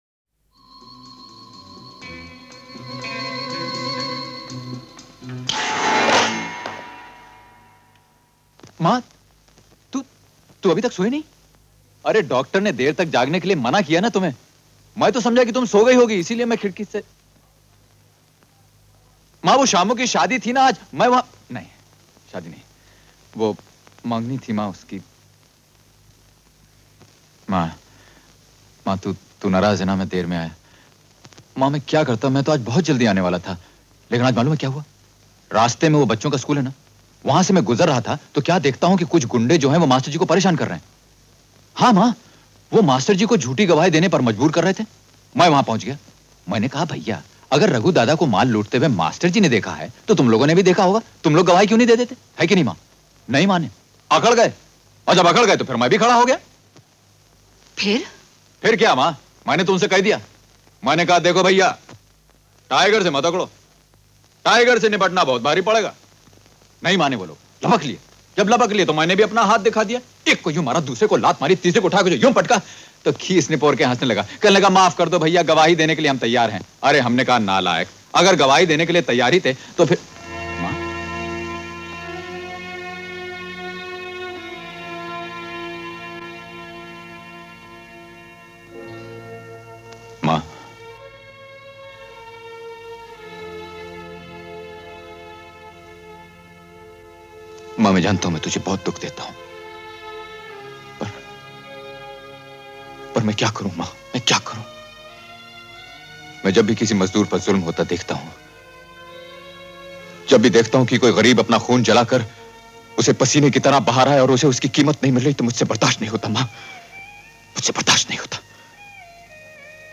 [Artist: Dialogue ]